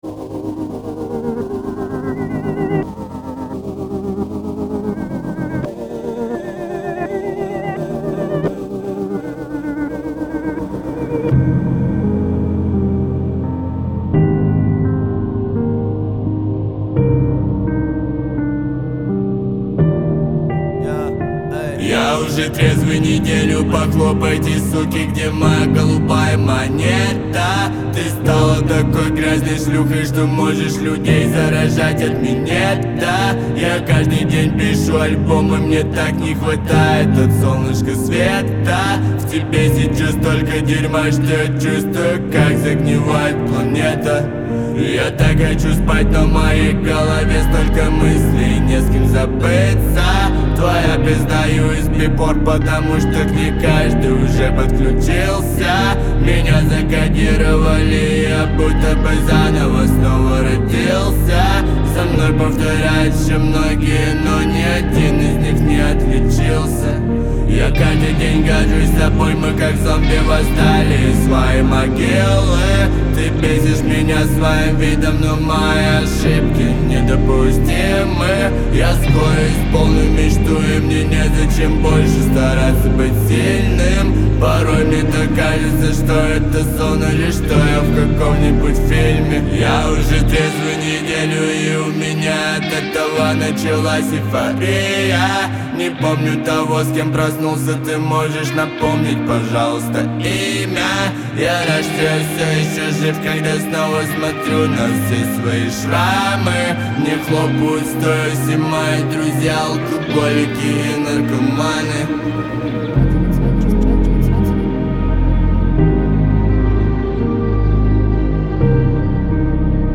Трек размещён в разделе Русские песни / Альтернатива / 2022.